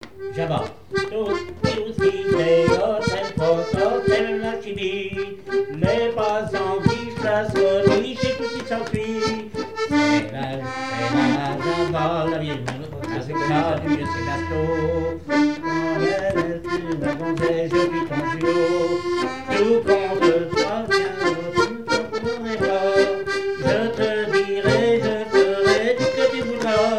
danse : java
Pièce musicale inédite